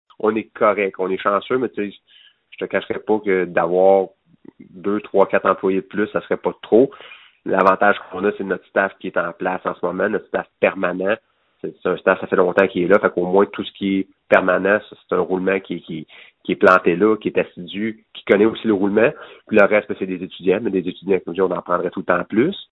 En entrevue